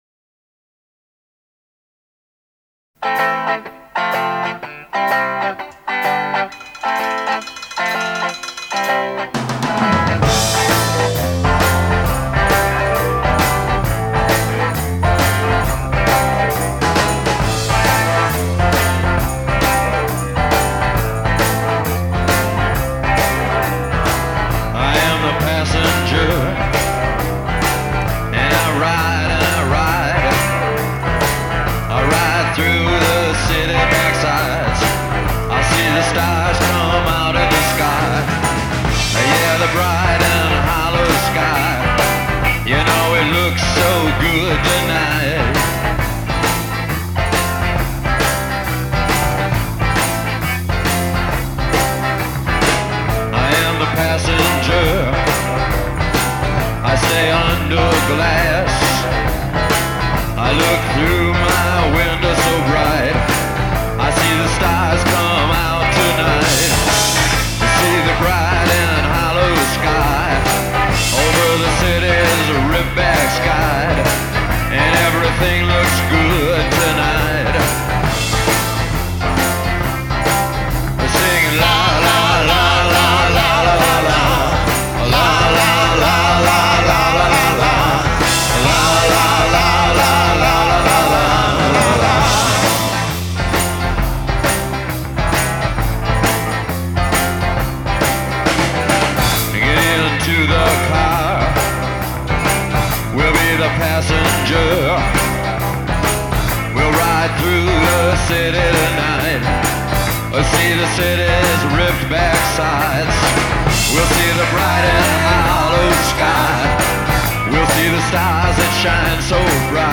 хорошая "маршевая" песня